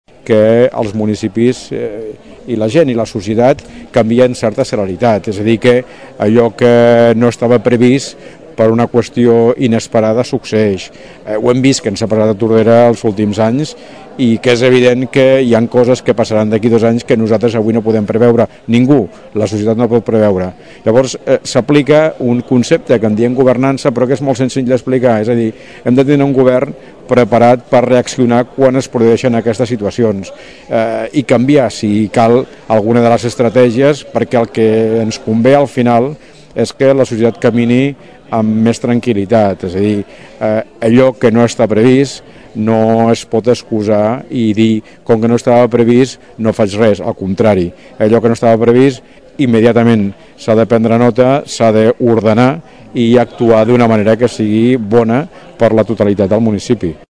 acte ciuJoan Carles Garcia, alcalde de Tordera i candidat a la reelecció per a les properes municipals, va presentar ahir el projecte de futur per al municipi en un acte públic al Teatre Clavé.